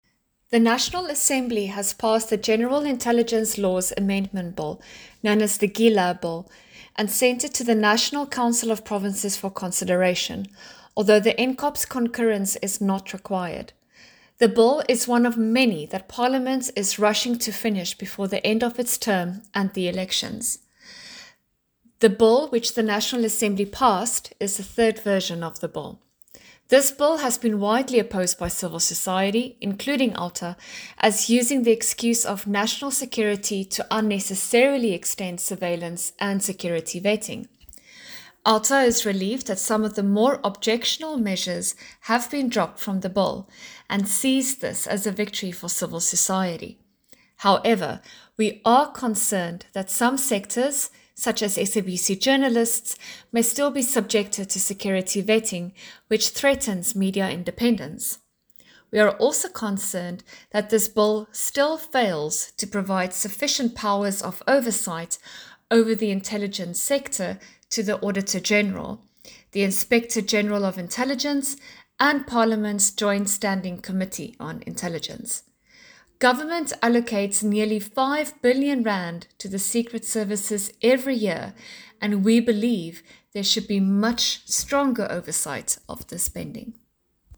A soundclip with comment in English